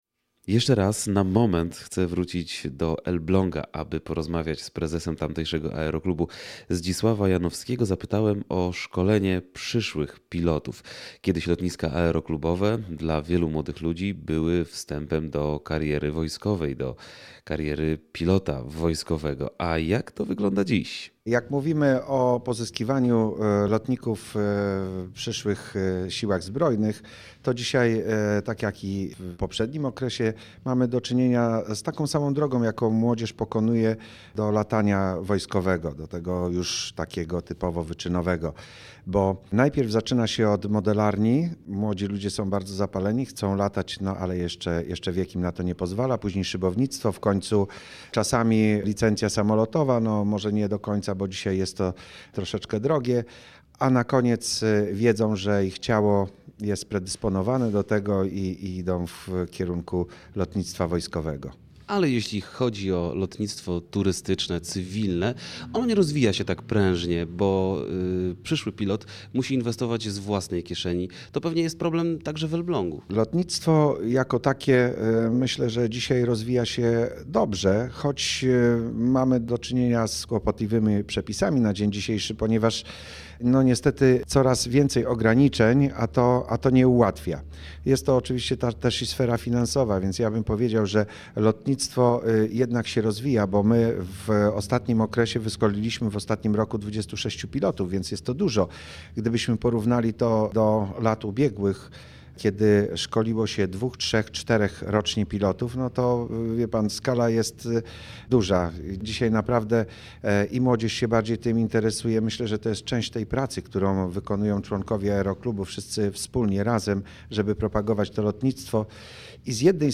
2009-06-13Relacja z Rajdu po Lotniskach Warmii i Mazur - Elbląg, cz.3 (źródło: Radio Olsztyn)